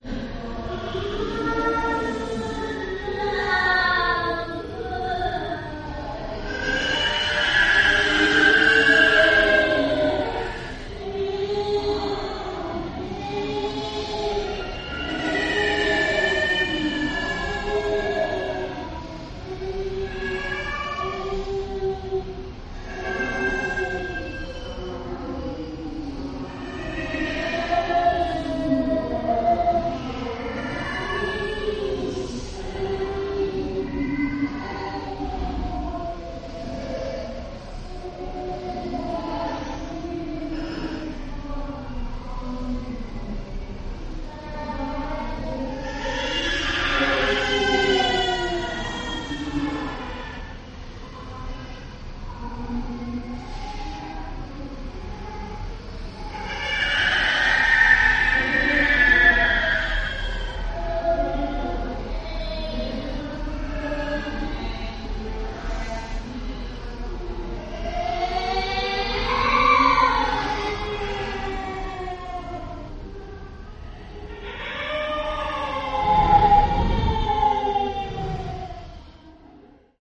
Звуки жуткие
Дети-призраки шепчут тебе на ухо